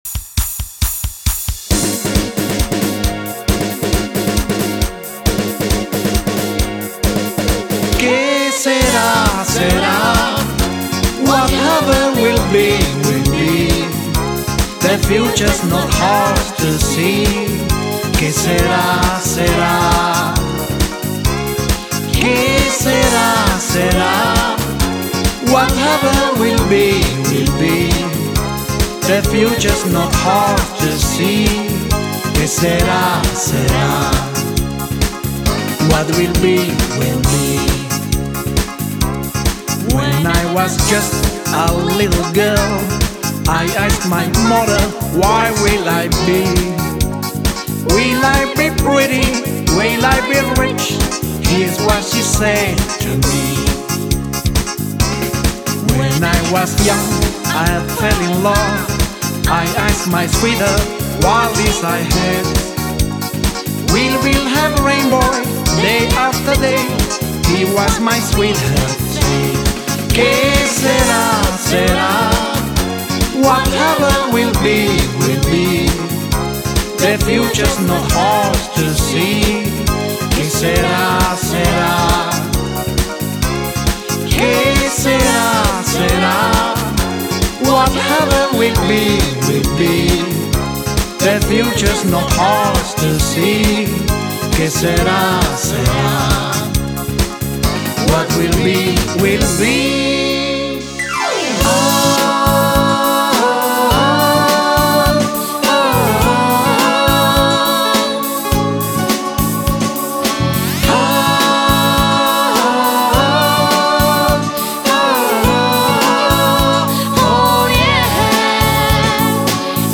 Trio
Medley
Gesungen